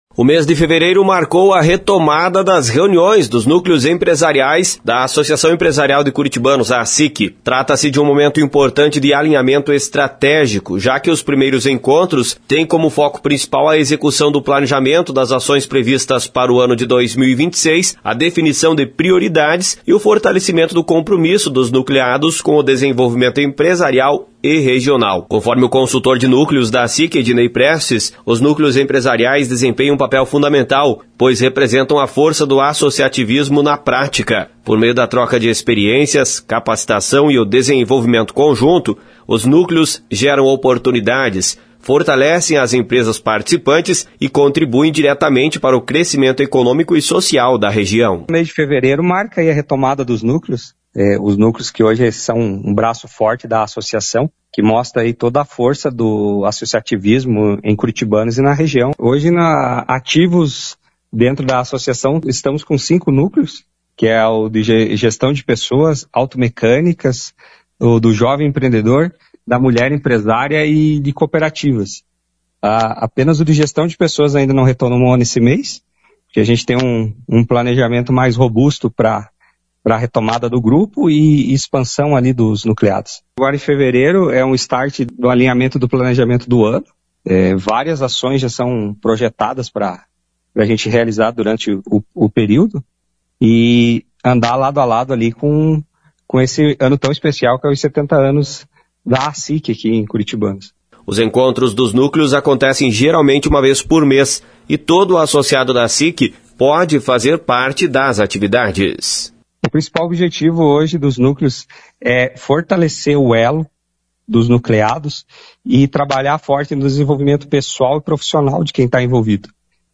Informações com o repórter